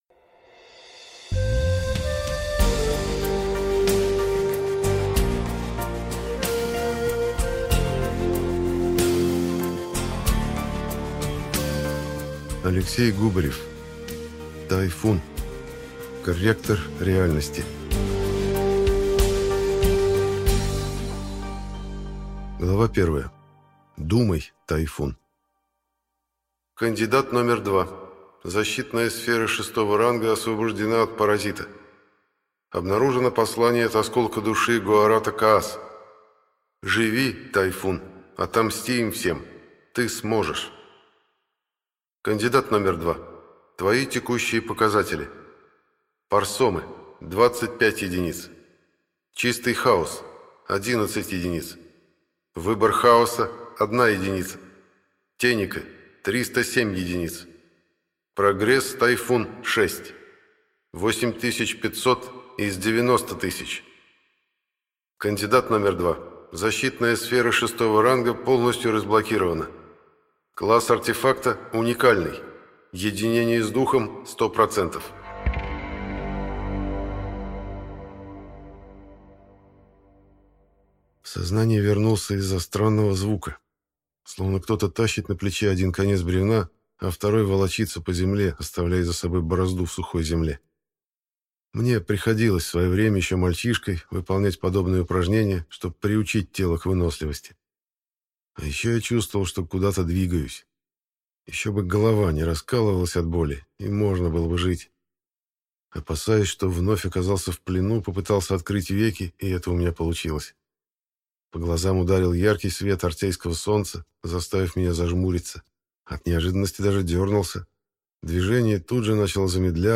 Аудиокнига Корректор реальности | Библиотека аудиокниг